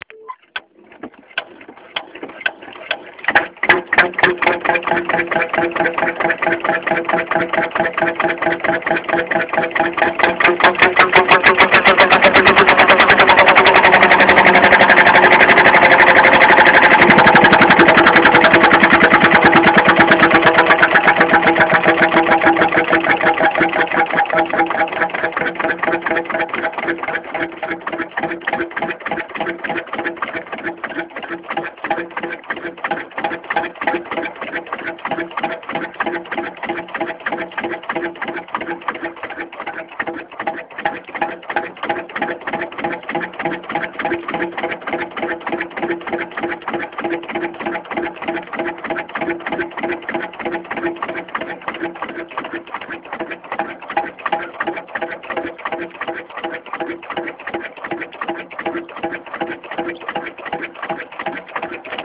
Det står i dag en 10 hesters Sabb type G i den.